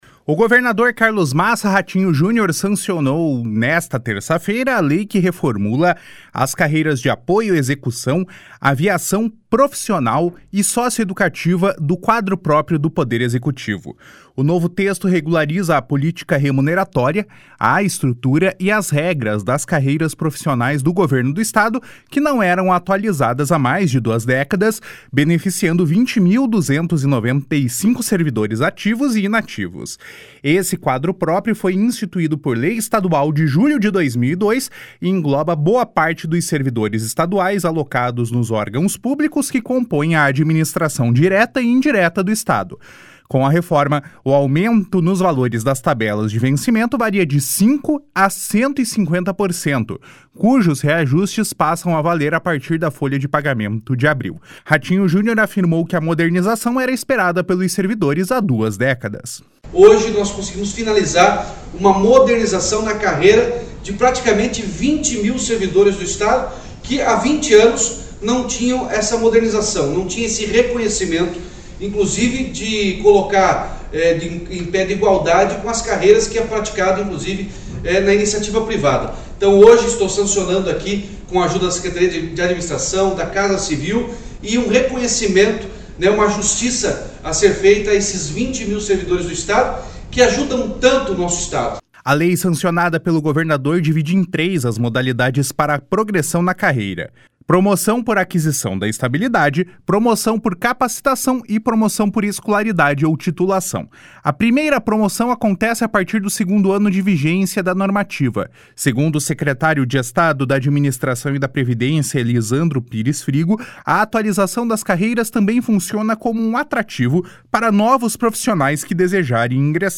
// SONORA RATINHO JUNIOR //
// SONORA ELISANDRO PIRES FRIGO //